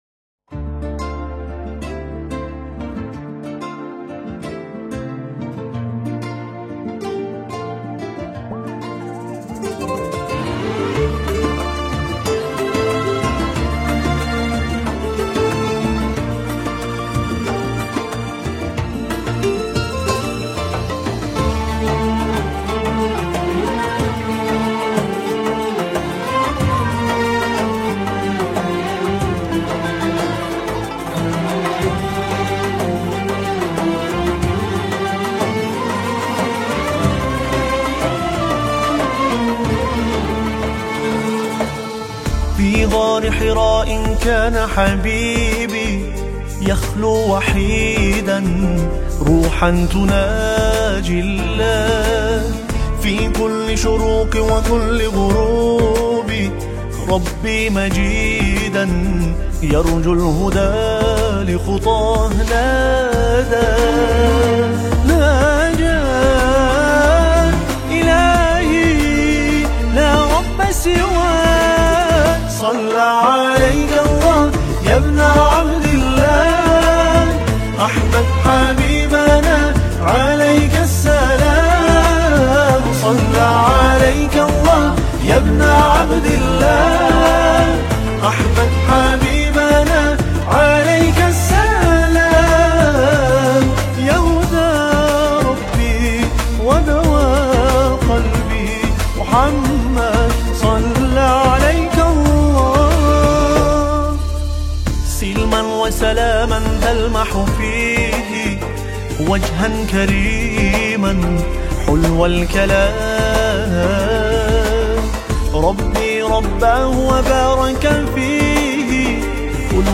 دانلود نماهنگ زیبای عربی